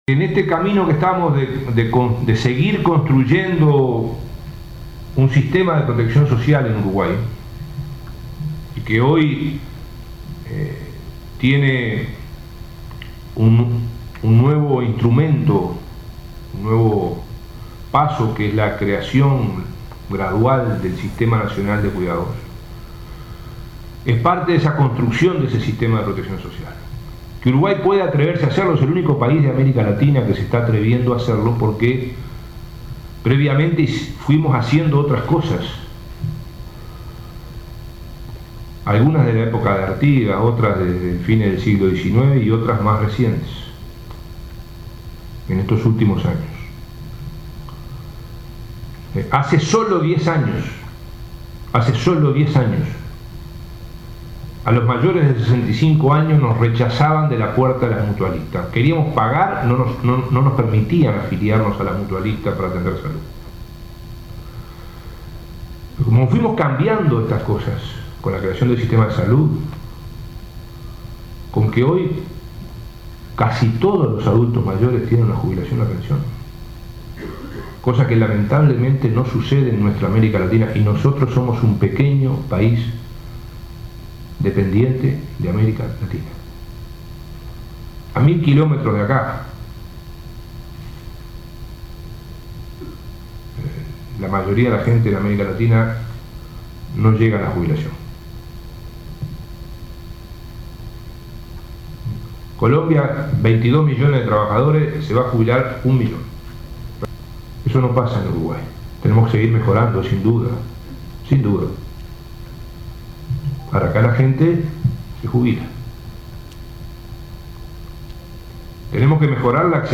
El ministro de Trabajo, Ernesto Murro, dijo que Uruguay es el primer país de América Latina que está construyendo un sistema de protección social, refiriéndose, entre otras cosas, al Sistema de Cuidados y a la reforma de salud. Al participar de la conferencia “Humanizar la vida de la persona mayor”, organizada por el Mides y la Fundación Astur, habló de teleasistencia, del móvil de podología y del acceso a nuevas tecnologías.